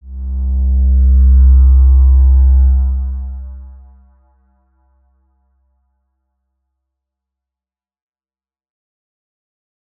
X_Windwistle-C1-mf.wav